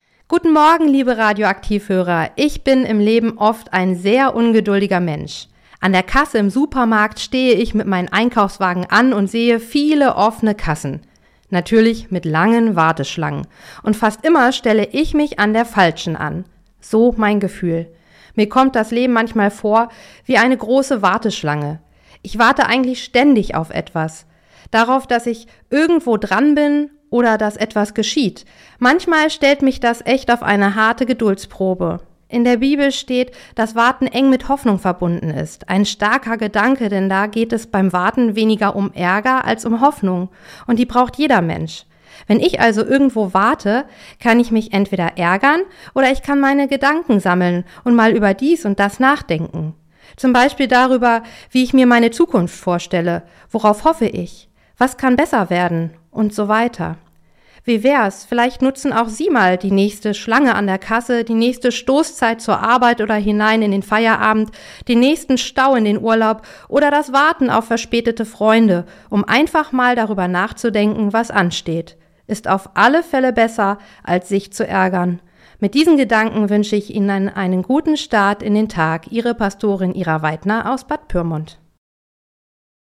Radioandacht vom 7. Juli